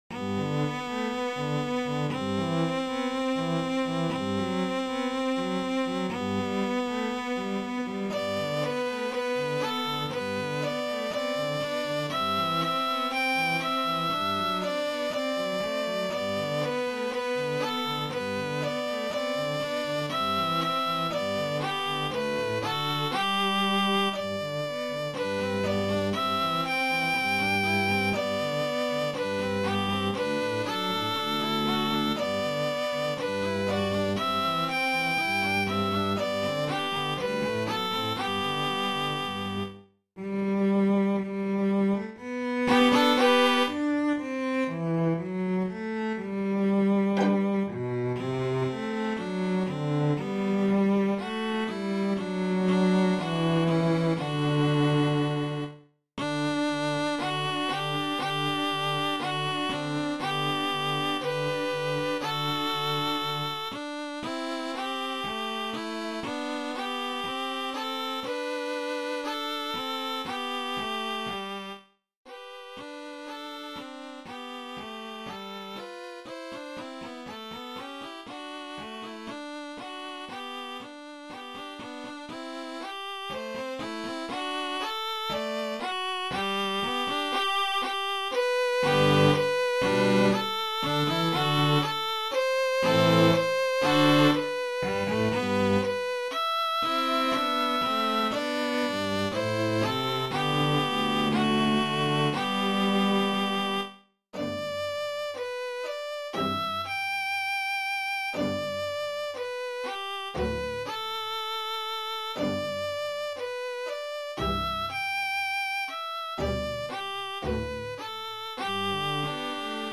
This elegant trio
hymns
Instrumentation: String Trio (Violin I, Violin II, Cello)